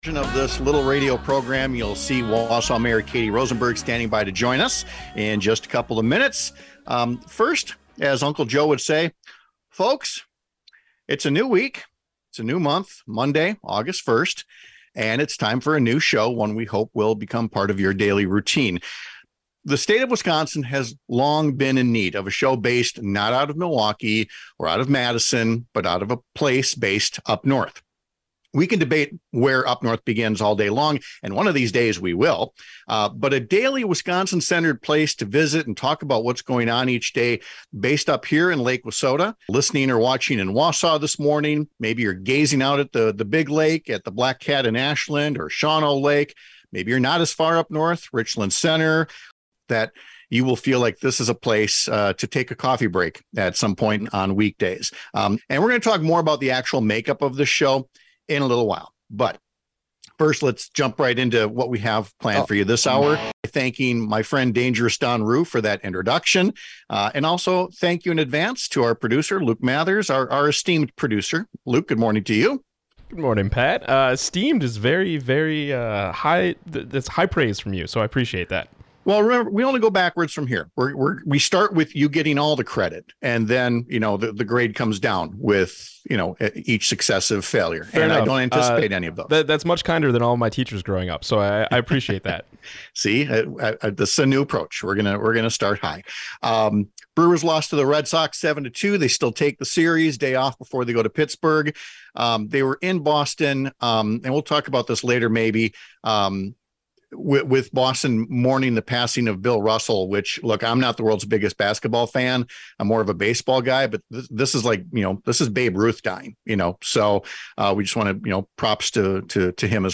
We kick off our brand new radio show with Wausau Mayor Katie Rosenberg.